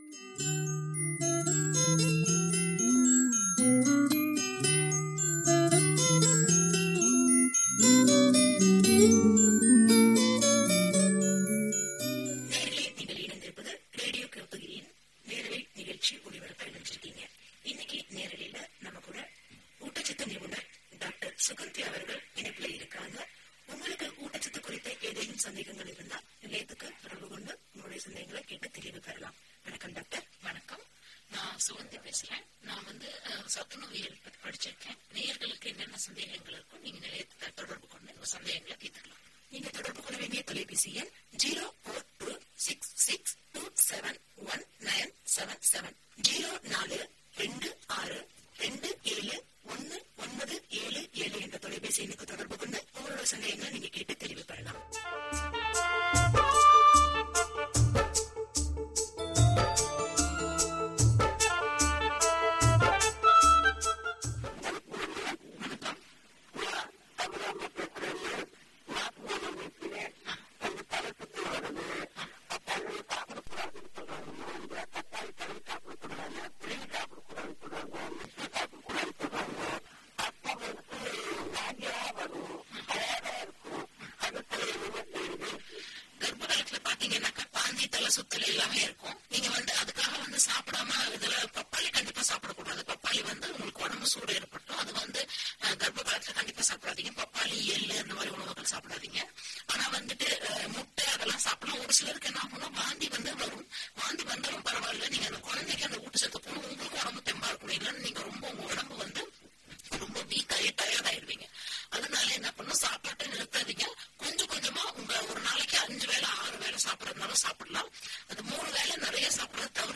24-4-17 Phone in Live_Field EP47.mp3